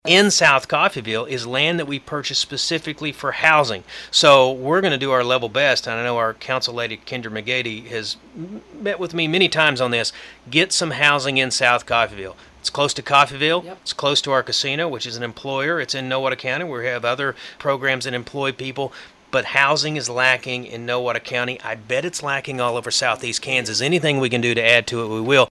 During a recent appearance on sister station KGGF in Coffeyville, Hoskin said the lack of housing can be considered a